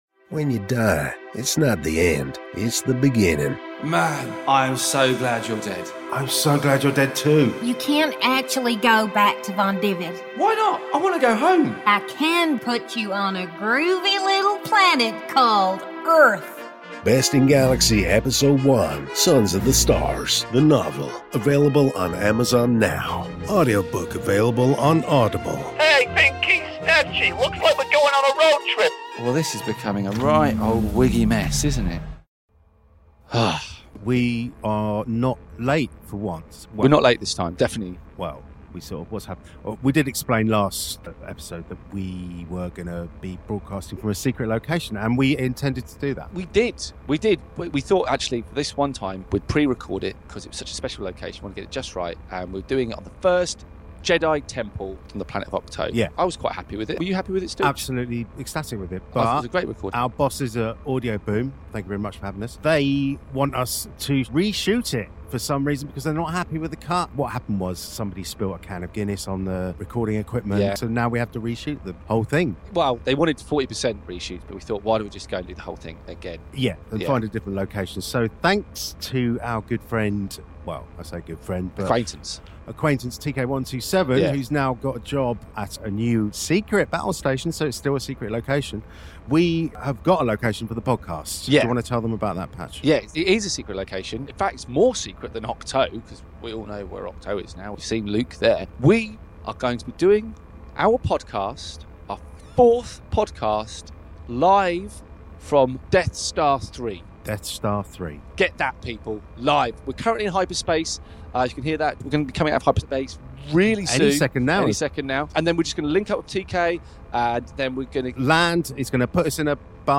This is a free parody podcast, not affiliated to Star Wars or Disney and done just for fun cos we just love Star Wars!